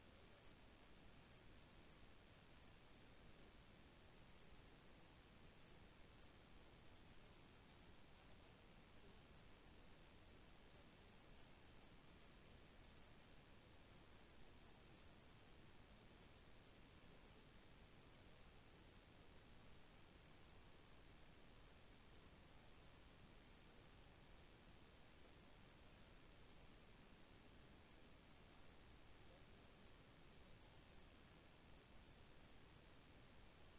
Homenagem ocorreu no início da sessão plenária desta segunda-feira (19).
SONORA deputado Fabio Oliveira (Podemos)